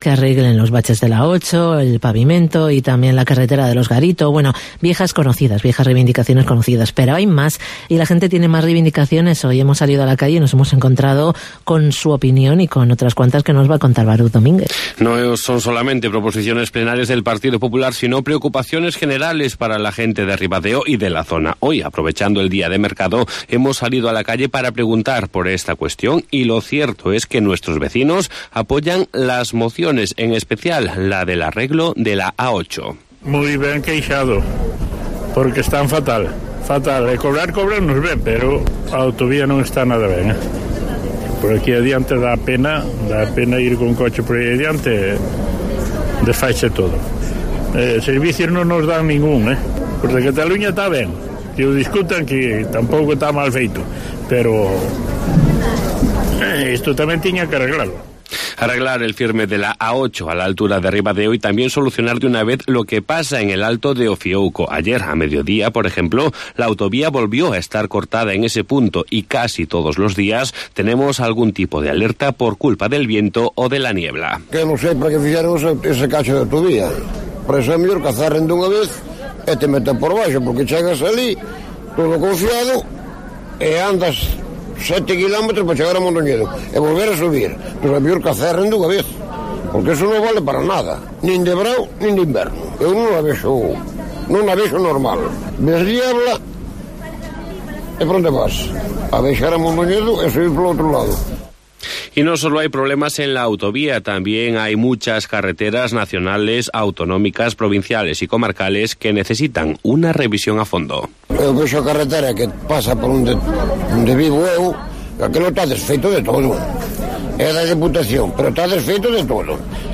ENCUESTA
El micro callejero de COPE de la Costa ha preguntado por nuestras vías públicas.